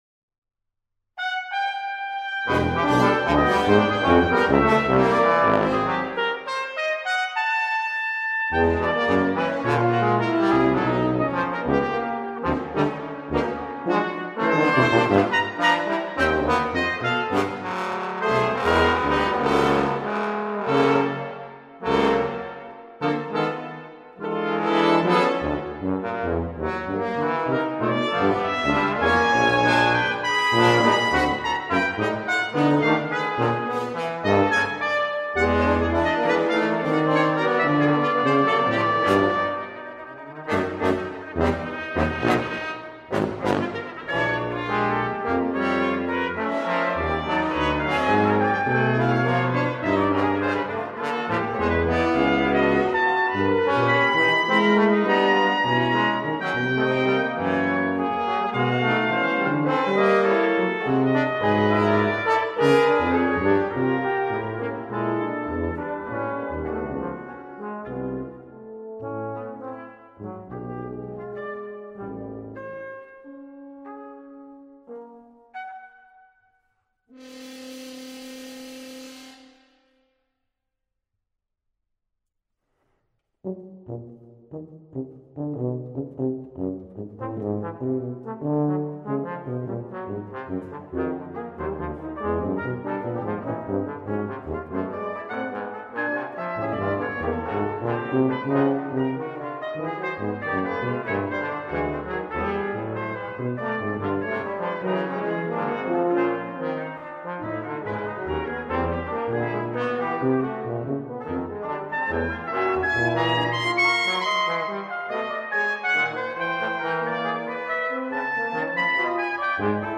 for brass quintet [1992] | duration: 9’00”